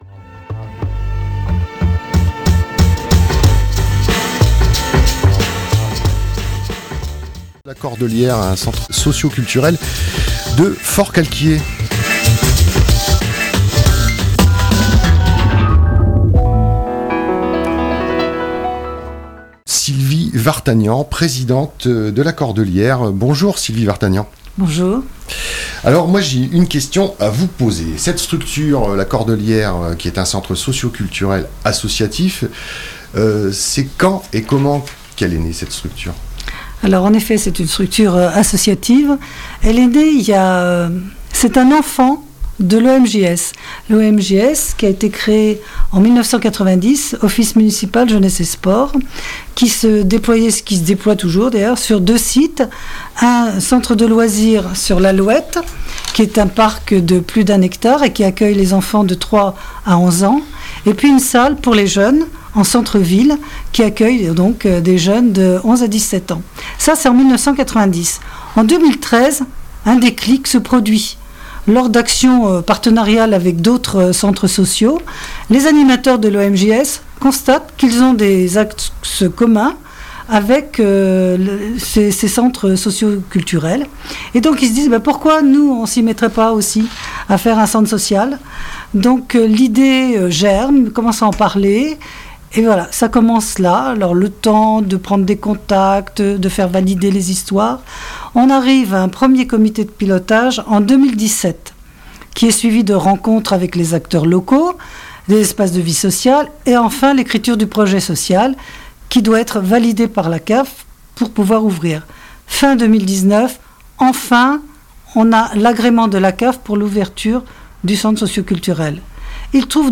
itv et réalisation